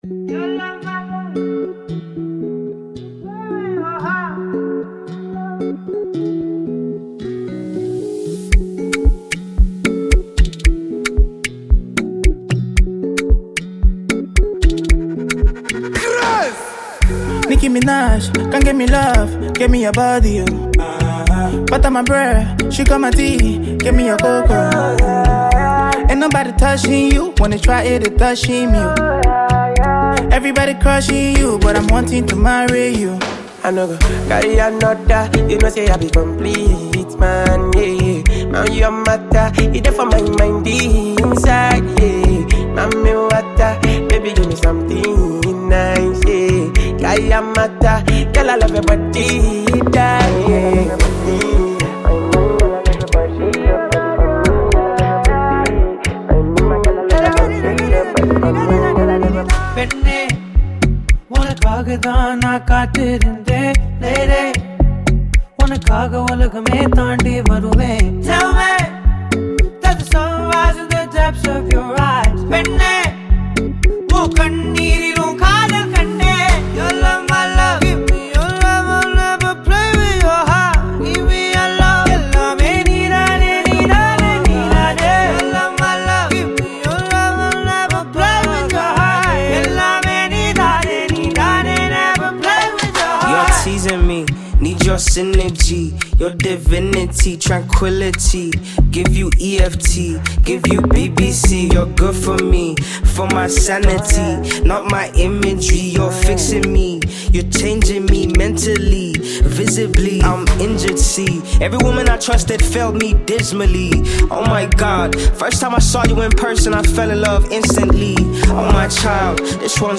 Enjoy this remix